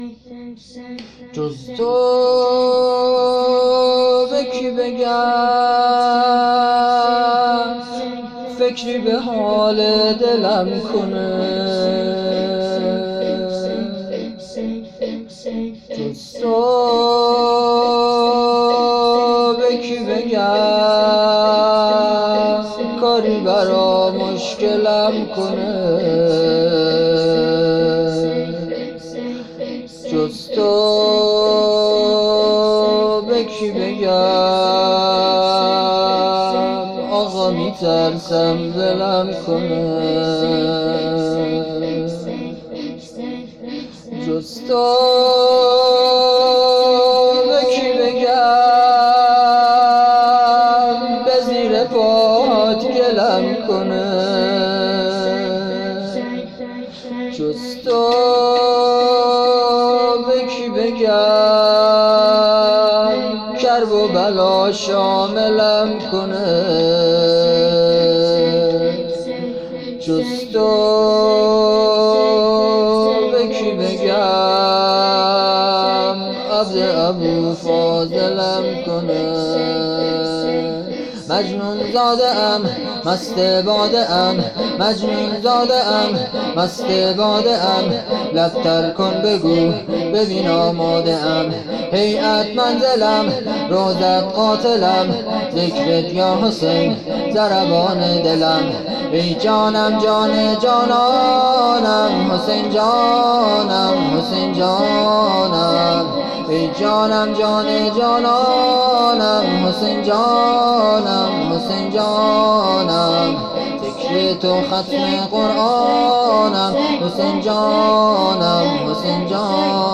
[شور] - جز تو به کی بگم - شب 2 محرم 1400
شور